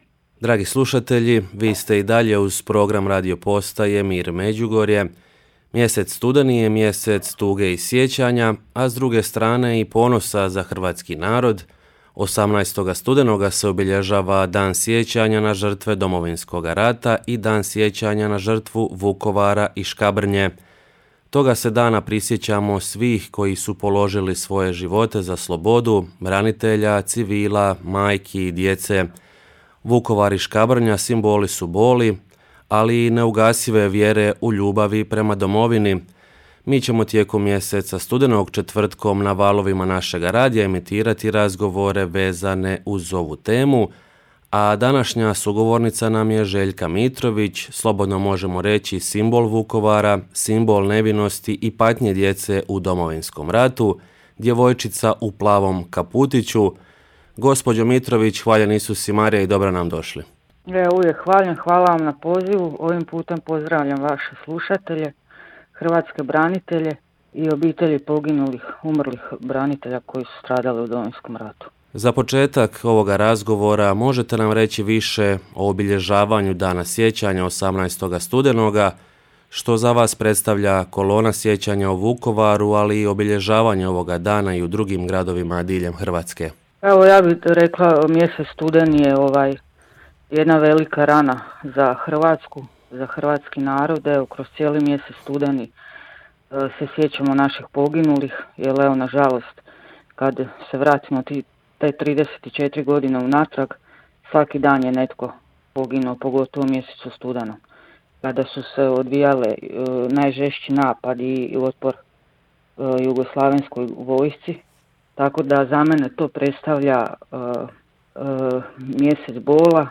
Tijekom studenog četvrtkom na valovima našega radija ćemo emitirati razgovore vezane uz ovu temu